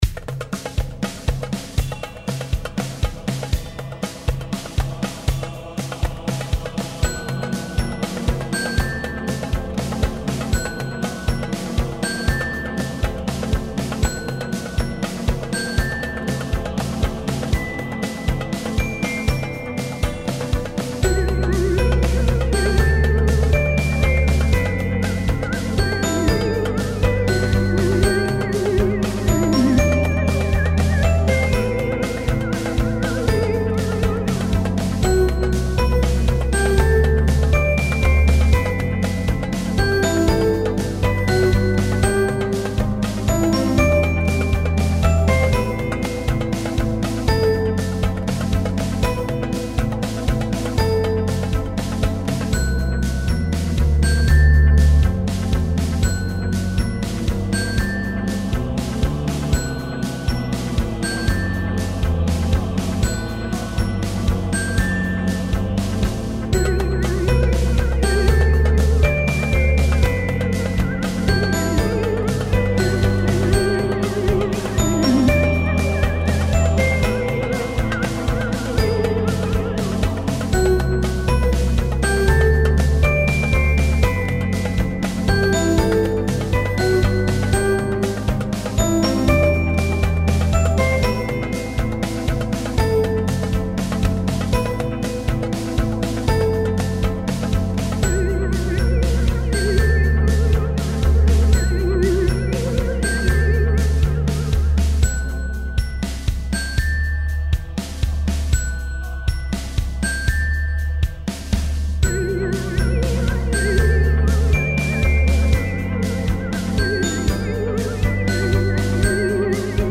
• 笛や太鼓、どこからともなく響く不協和音
• リズム構成：軽快なパーカッションが曲全体を引っ張ります。やや跳ねるようなノリ。
• コード進行：長調ベースですが、部分的に短調や不協和音を挟むことで“不穏さ”を表現。
• メロディ：オルゴール風のシンセ音、マリンバ、アコーディオン系サウンドなどを使用。
フリーBGM 不気味 ゲームBGM ファンタジー ミステリー お祭り